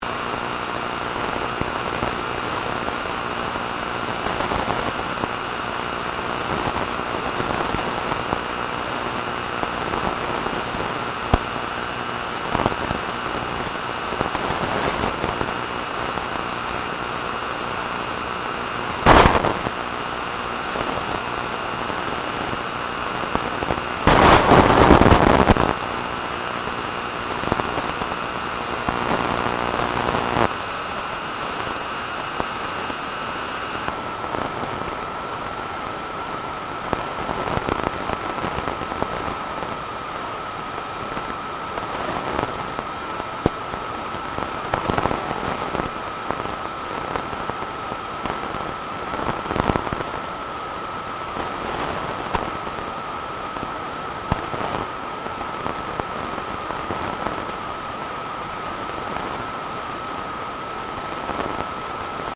timothy_geithner_briefing.mp3